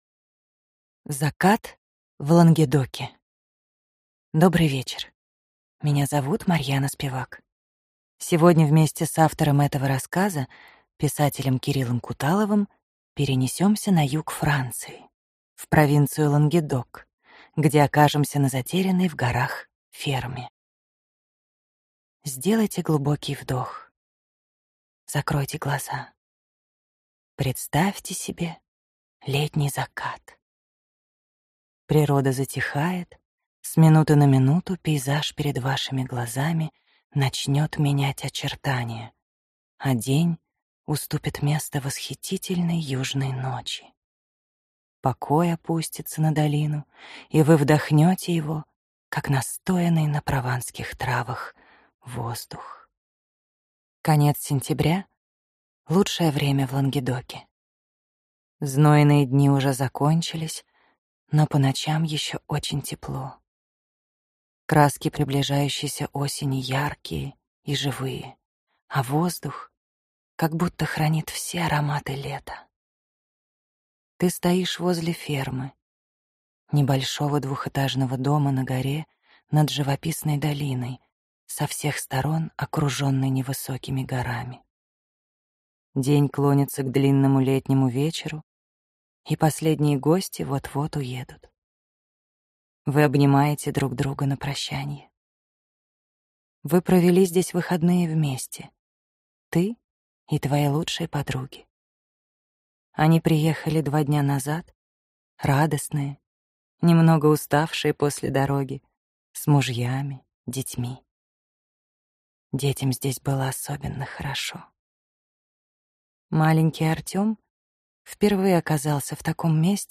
Аудиокнига Закат в Лангедоке | Библиотека аудиокниг